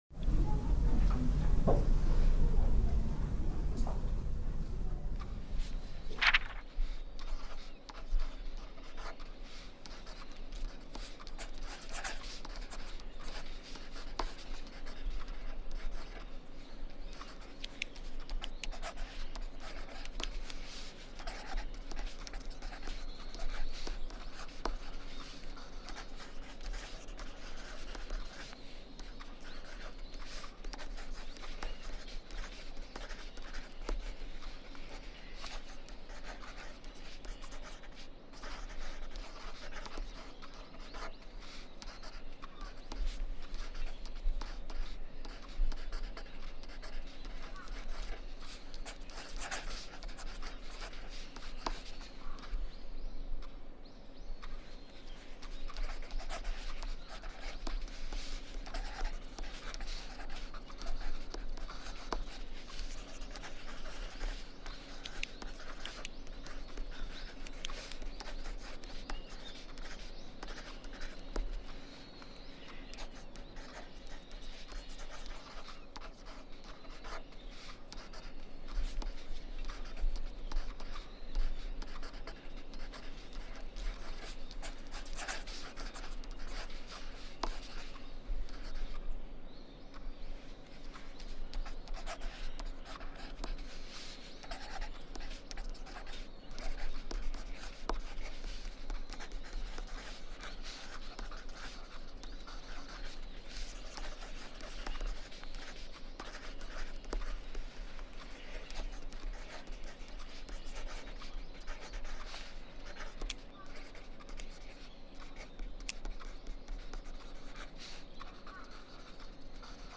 Writing Ambience – 70-Minute Timer for Exam Preparation